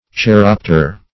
Search Result for " cheiropter" : The Collaborative International Dictionary of English v.0.48: cheiropter \chei*rop"ter\ (k[-i]*r[o^]p"t[~e]r), n. (Zool.)